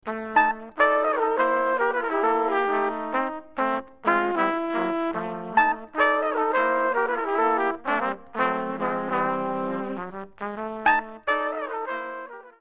3/4 swing (medium)